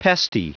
Prononciation du mot pesty en anglais (fichier audio)
Prononciation du mot : pesty